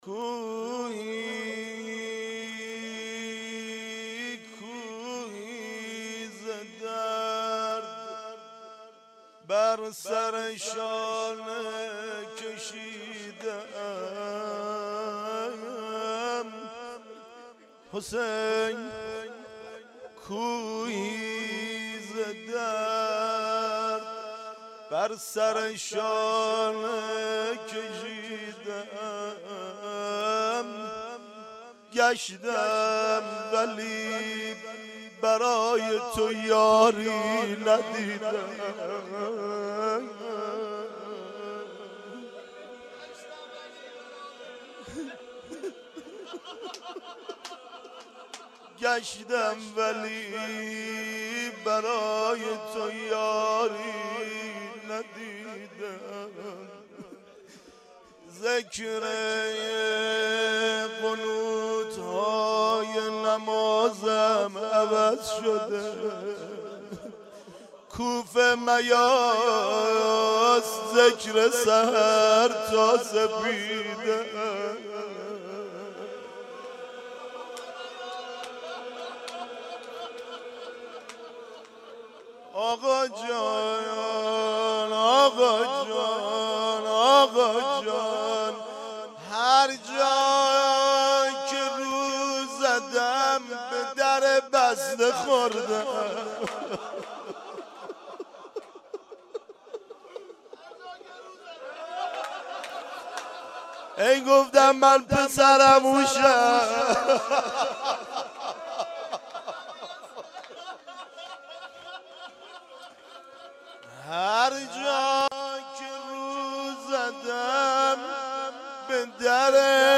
کوهی ز غم بر سر شانه کشیده ام | روضه